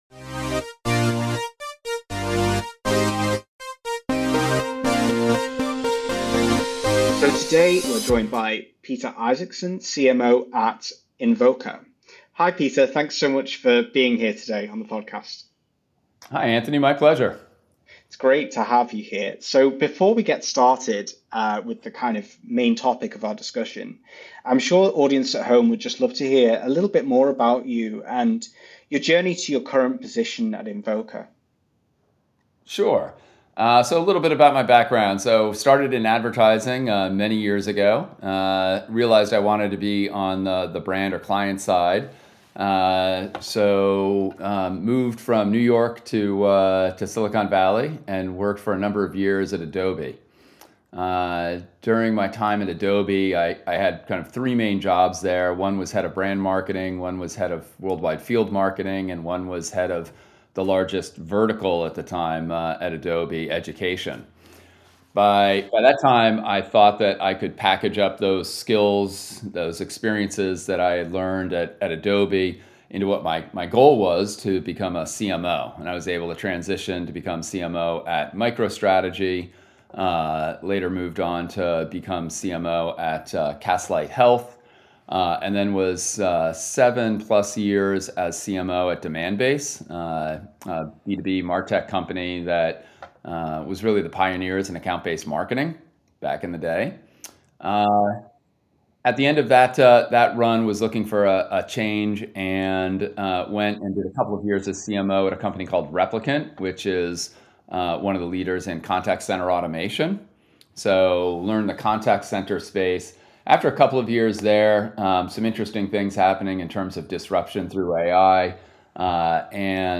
In each episode, we have in-depth conversations with CMOs and top-level marketers from around the world, across every industry and level of experience, in order to get their insights into what it takes to excel at the very top of the marketing hierarchy.